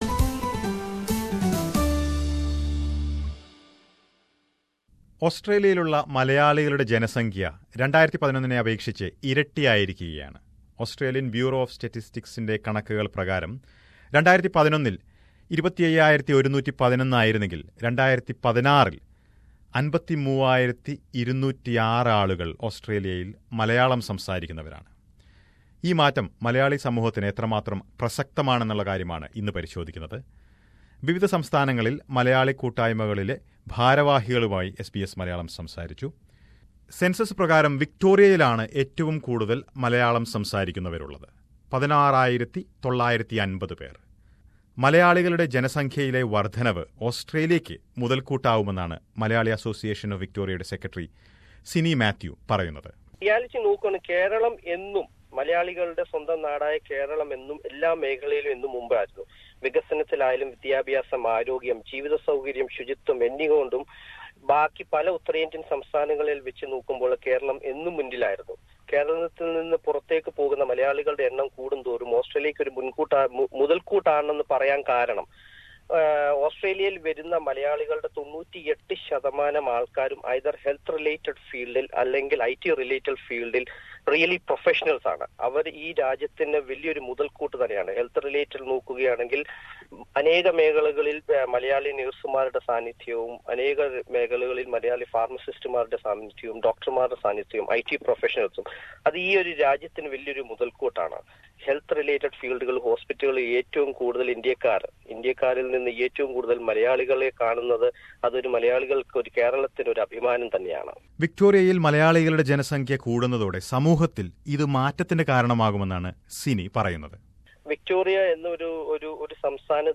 ഓസ്‌ട്രേലിയയുടെ വിവിധ ഭാഗങ്ങളിലുള്ള മലയാളി കൂട്ടായ്മയുടെ പ്രതിനിധികളോട് എസ് ബി എസ് മലയാളം ഇതേക്കുറിച്ച് സംസാരിച്ചത് കേൾക്കാം, മുകളിലെ പ്ലെയറിൽ നിന്ന്.